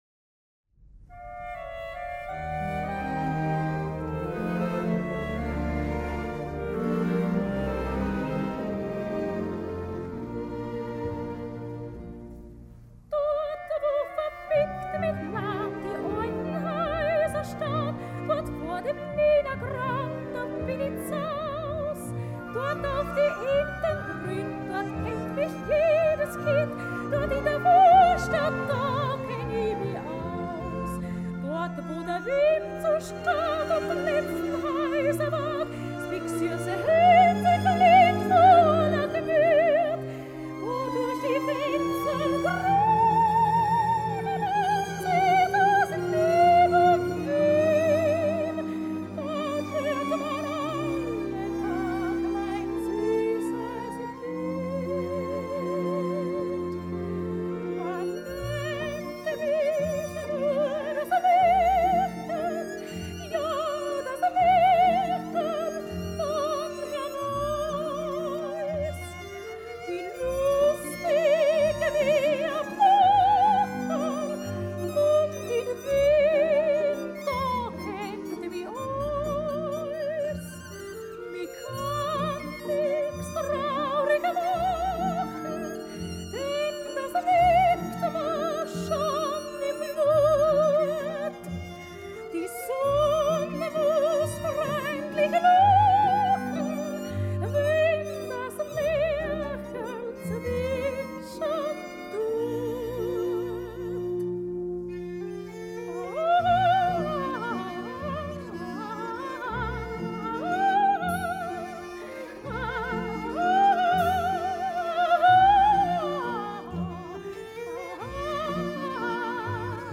Operette in drei Akten
Lisi, seine Tochter Sopran